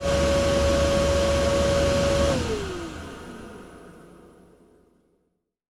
VENTILATR2-S.WAV